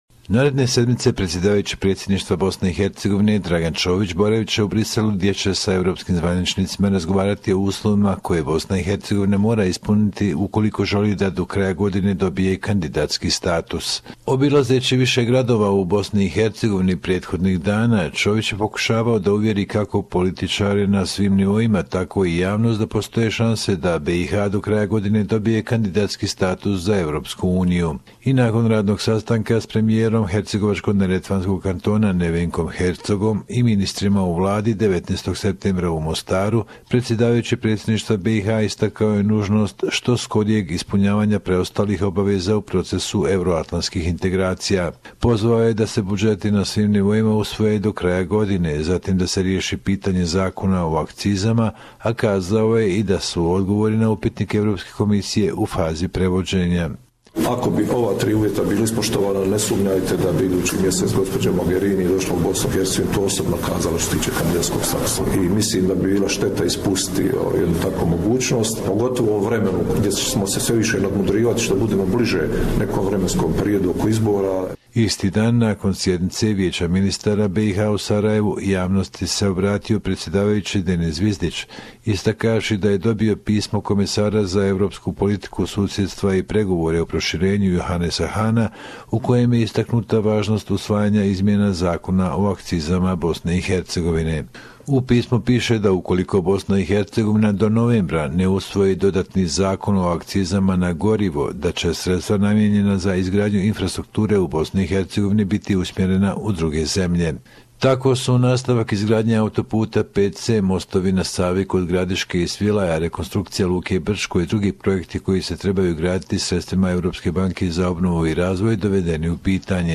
Bosnia and Herzegovina / radio report